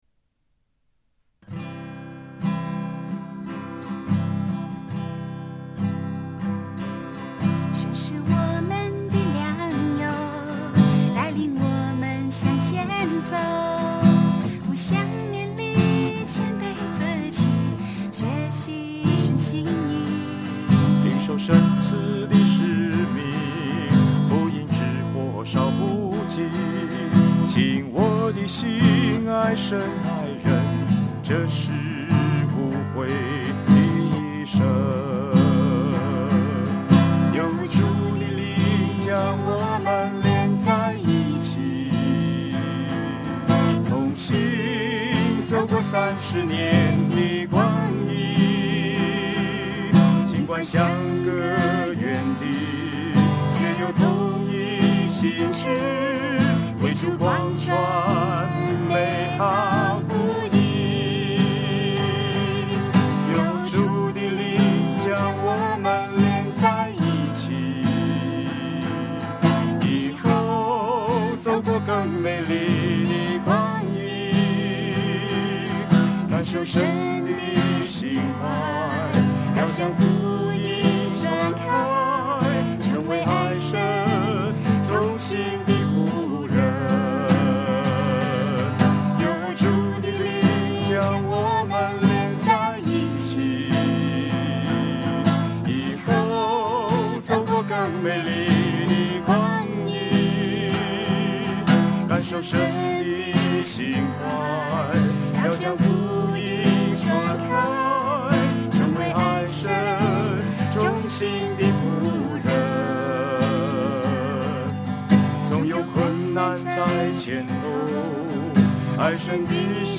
男女声合唱 |